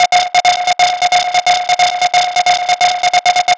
Lead 134-BPM F.wav